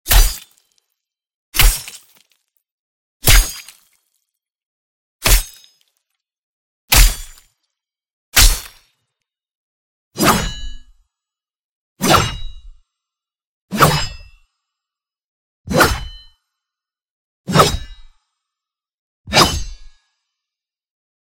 The Scarlet Oath Mp3 Sound Effect Fortnite | The Scarlet Oath Pickaxe Sounds!